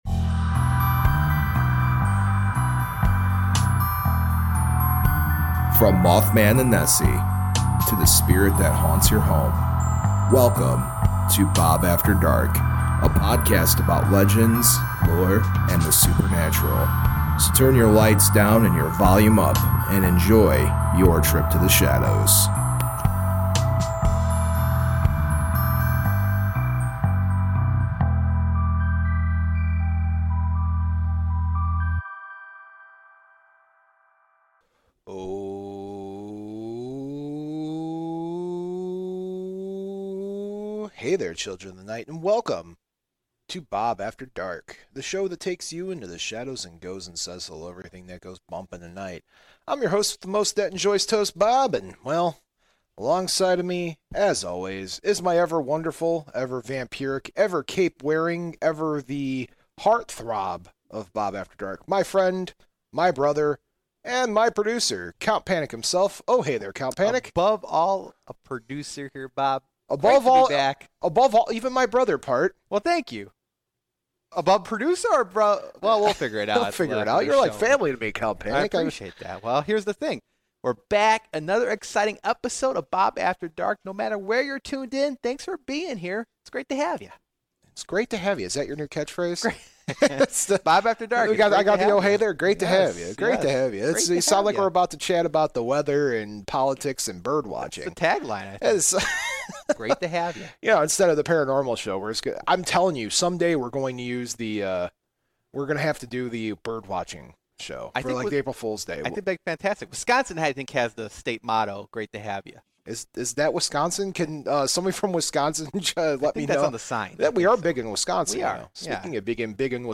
We are trying to get more interviews done, and get more stories being shared.